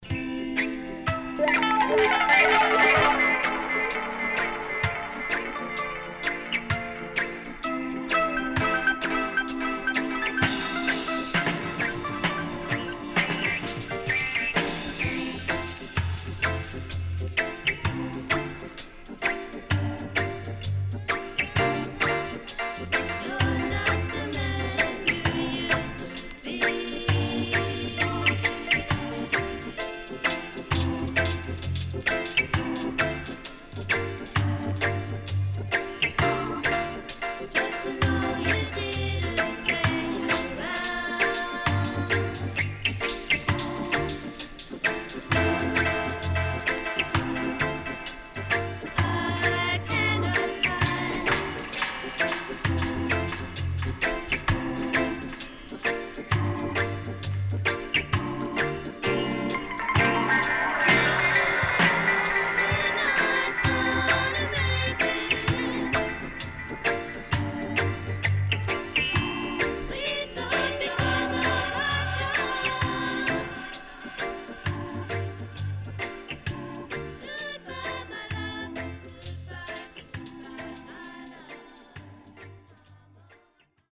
Top Lovers Rock
SIDE A:少しチリノイズ入ります。